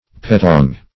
petong - definition of petong - synonyms, pronunciation, spelling from Free Dictionary Search Result for " petong" : The Collaborative International Dictionary of English v.0.48: Petong \Pe*tong"\, n. (Metal.)